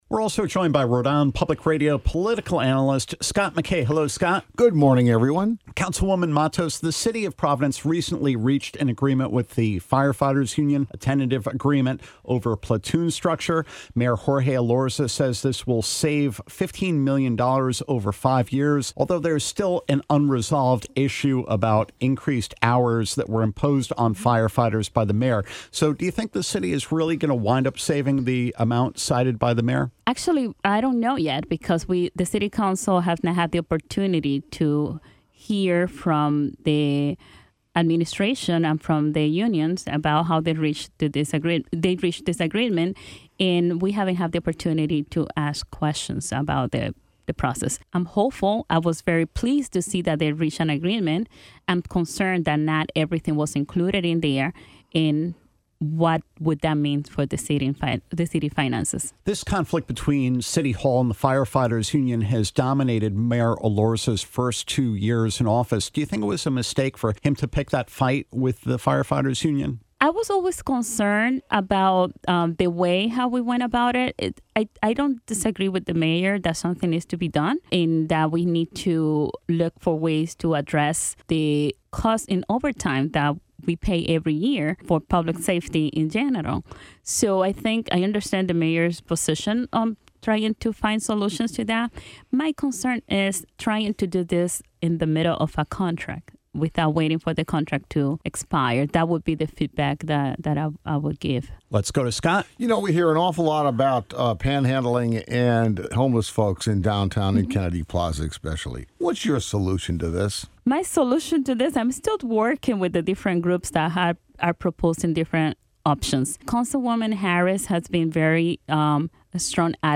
Ward 15 Providence Councilor Sabina Matos joins Bonus Q&A to talk about city finances, the thaw in the dispute with firefighters, Kennedy Plaza, charter schools, and more.